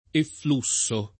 efflusso [ effl 2SS o ] s. m.